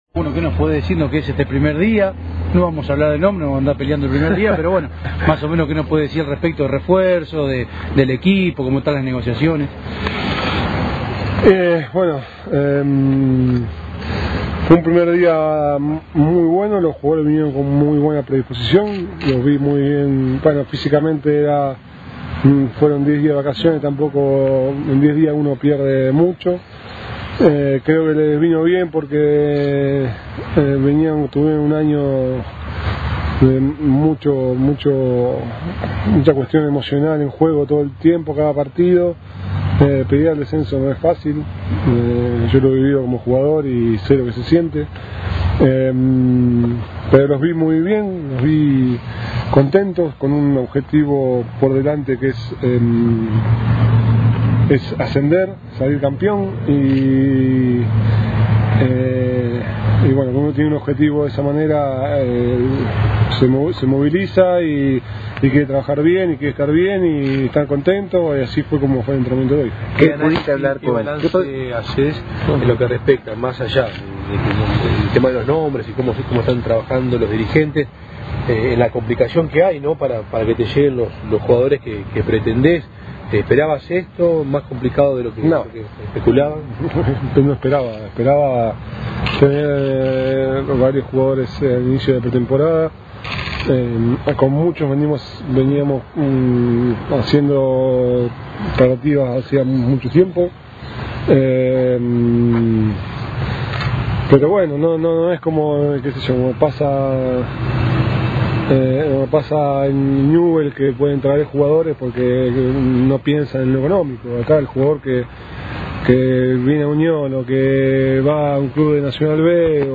El plantel tatengue regresó a los entrenamientos en el predio Casasol, de cara la próxima temporada en la B Nacional. Facundo Sava dialogó con la prensa y demostró su descontento por las pocas incorporaciones. Escuchá la palabra del técnico.
Declaraciones de Facundo Sava – SOL 91.5